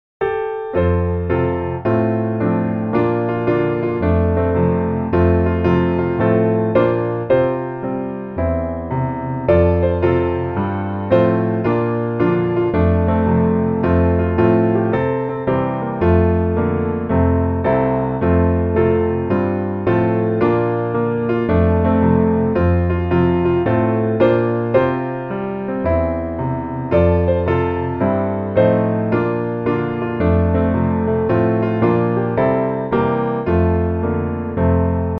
F Dur